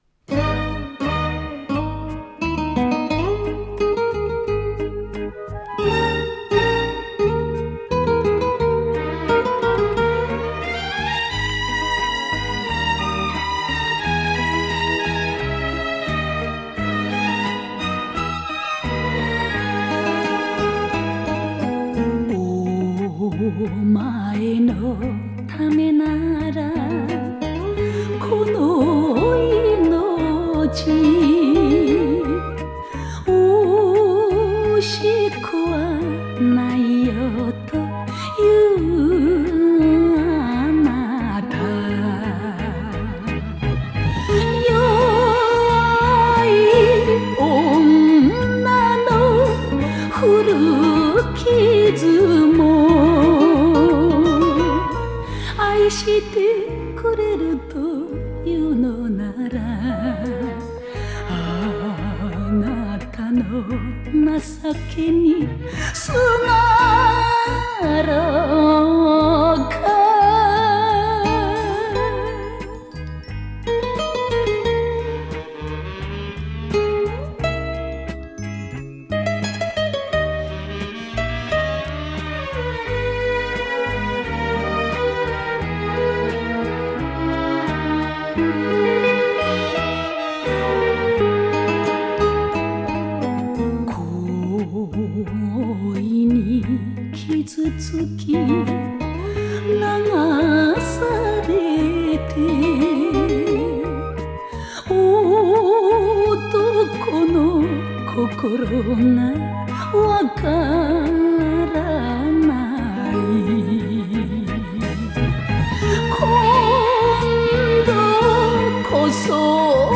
picЖанр: Enka